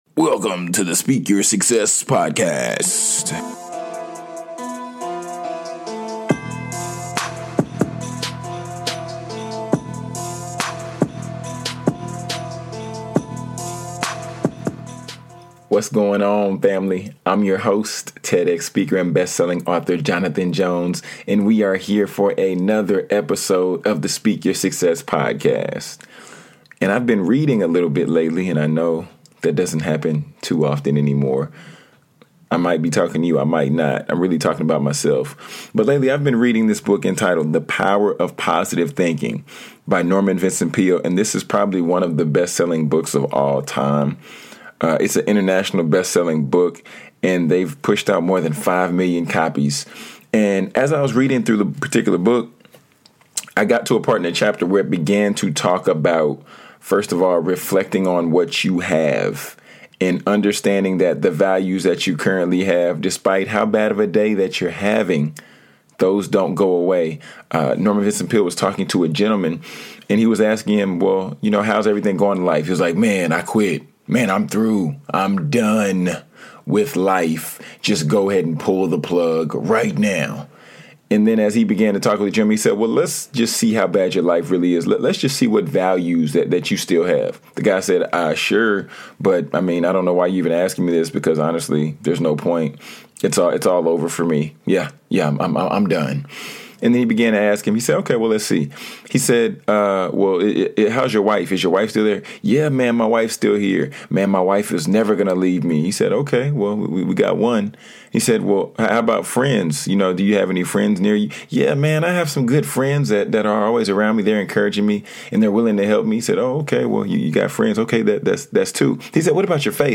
"FUEGO" DEEP HARD TRAP BEAT